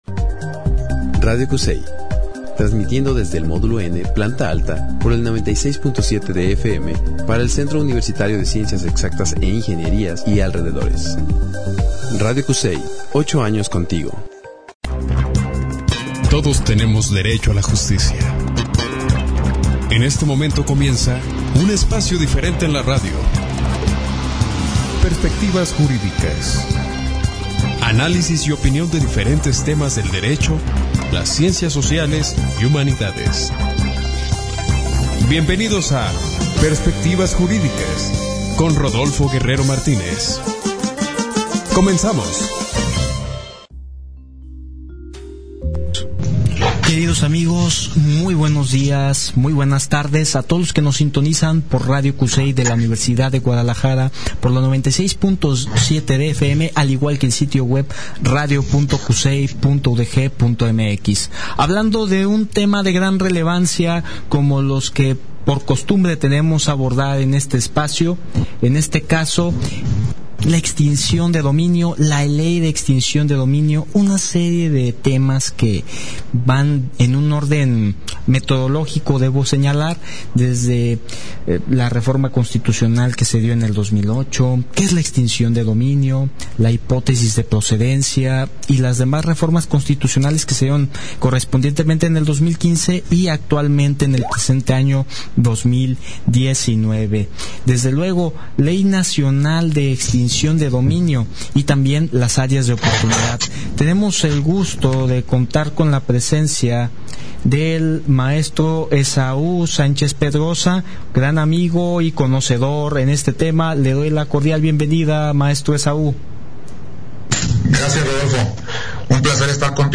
entrevista-extincic3b3n-de-dominio-i.-perspectivas-juridicas.mp3